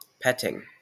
Ääntäminen
IPA : /ˈpɛtɪŋ/ IPA : [ˈpʰɛɾɪŋ]